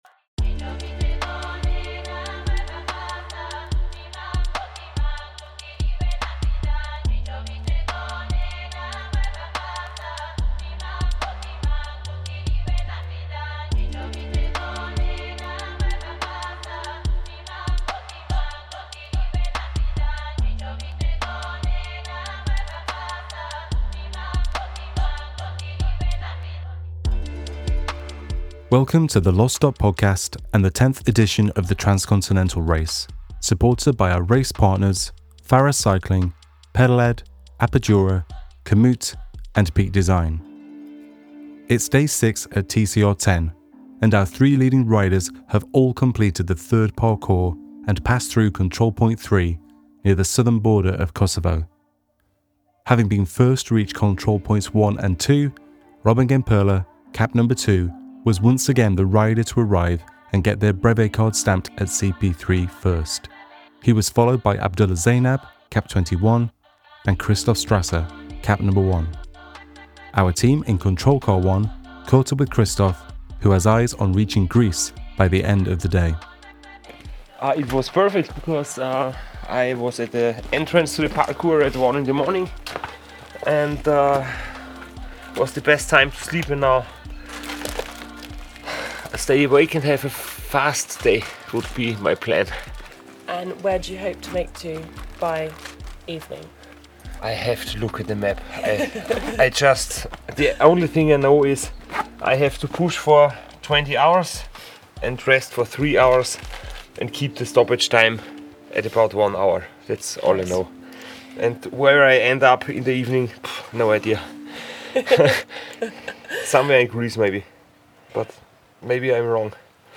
Control Car 2 is stationed at CP2 in Bosnia, and riders, locals, and volunteers share their stories.